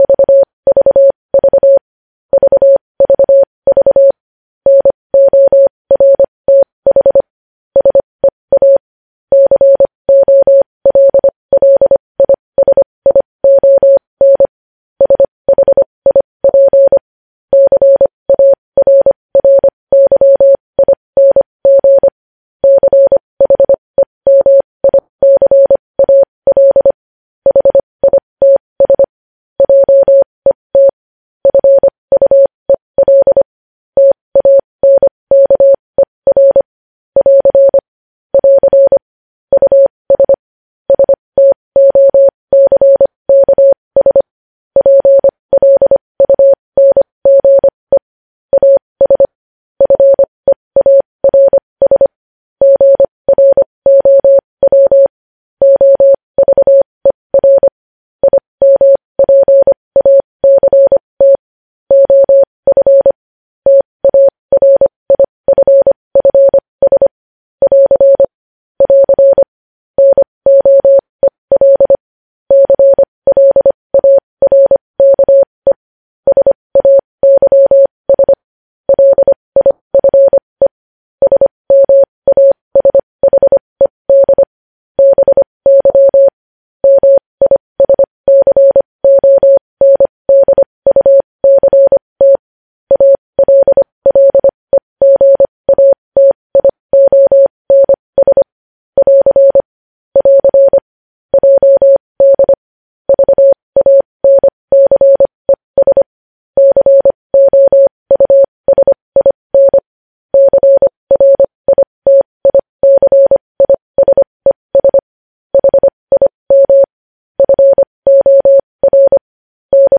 News Headlines at 20 – News Headlines in Morse Code at 20 WPM – Lyssna här